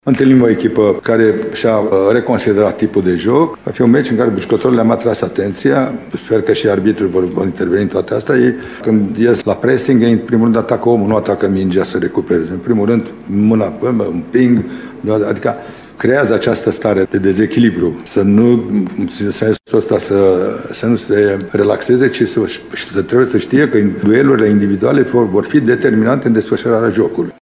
Mircea Lucescu a vorbit și despre „duelurile individuale”, pe care le consideră „determinante”: